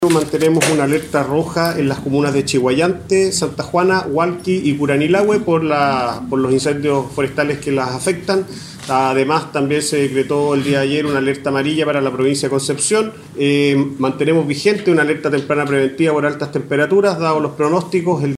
El director de la Onemi en el Bío Bío, Alejandro Sandoval, entregó información con respecto a las Alertas Rojas que se encuentran vigentes en las cuatro comunas afectadas: Chiguayante, Curanilahue, Hualqui y Santa Juana.